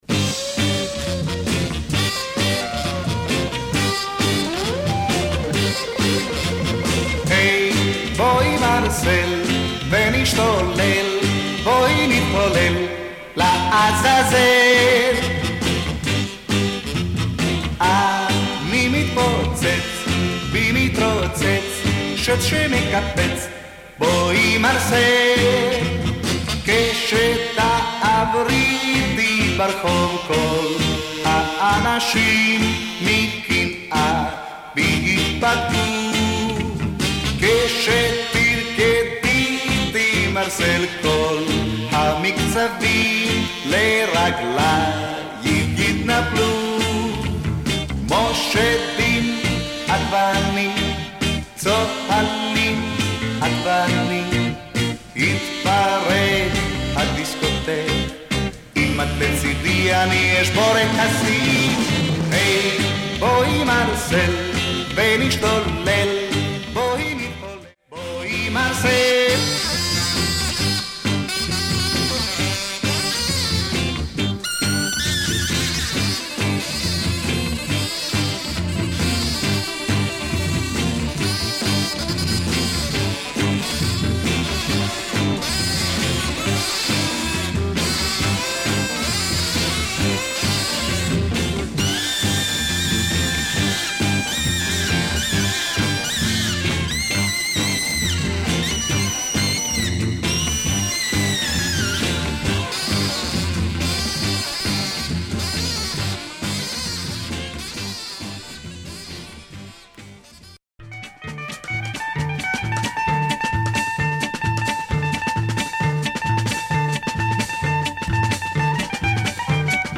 Nice 60's Israeli psych beat.